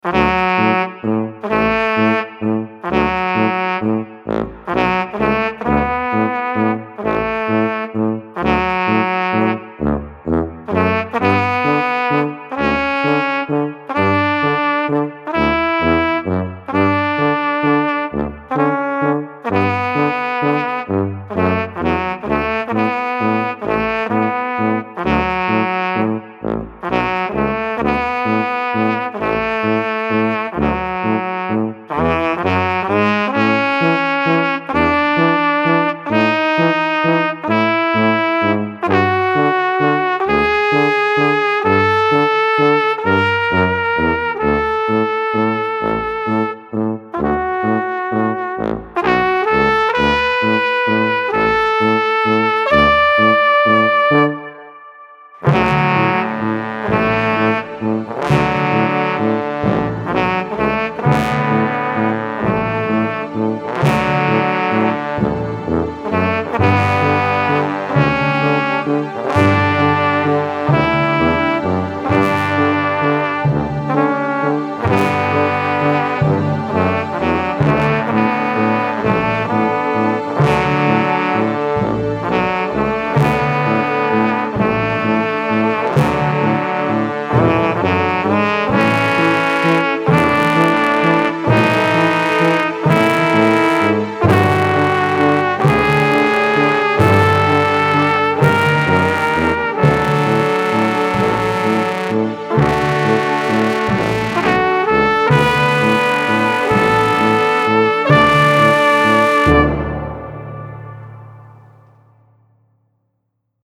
Mood Mood Funny, Relaxed
Featured Featured Brass, Drums
BPM BPM 130